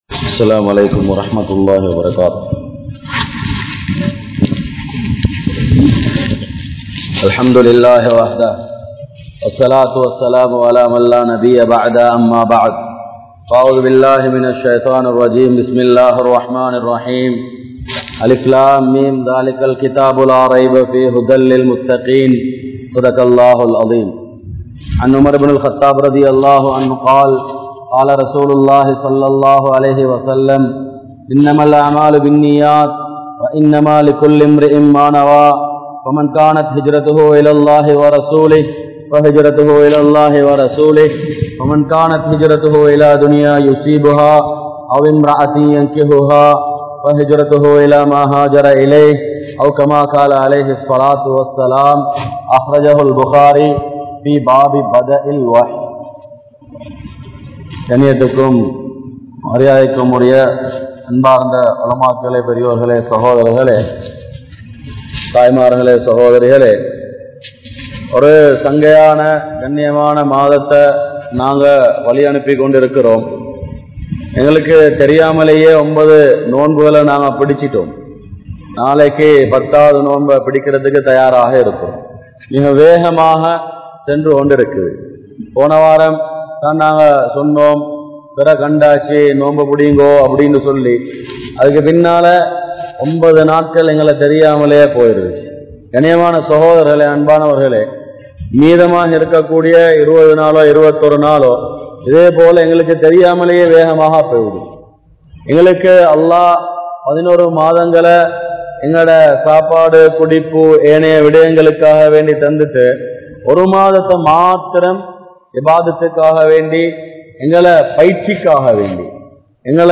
Al-Quran Koorum Noai Nivaaranihal(அல் குர்ஆன் கூறும் நோய் நிவாரணிகள்) | Audio Bayans | All Ceylon Muslim Youth Community | Addalaichenai
Grand Jumua Masjith